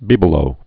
(bēbə-lō, bē-blō)